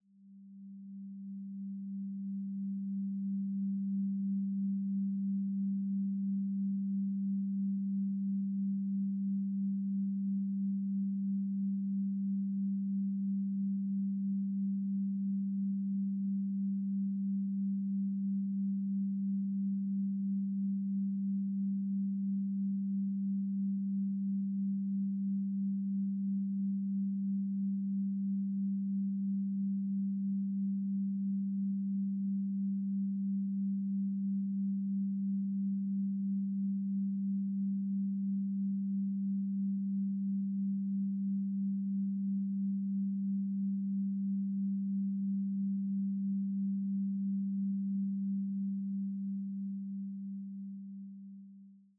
Duration: 0:52 · Genre: Ambient Electronic · 128kbps MP3